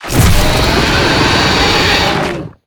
Sfx_creature_chelicerate_roar_02.ogg